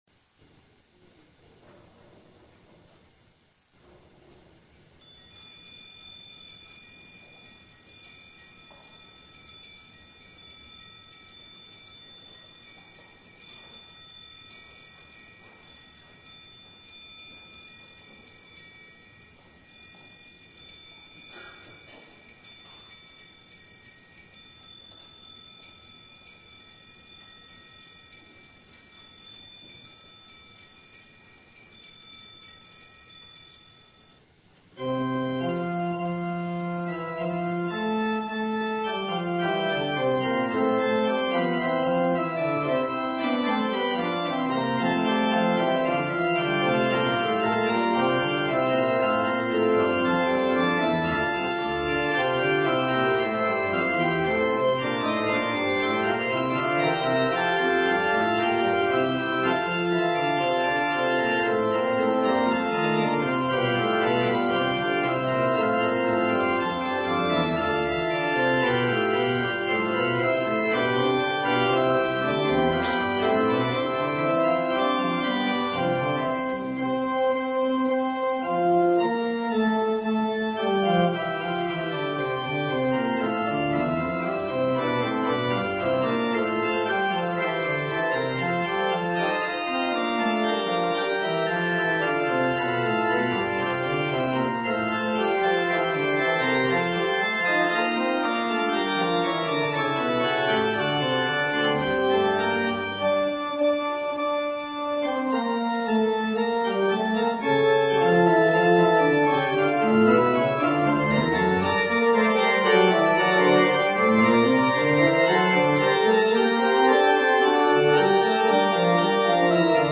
organ prelude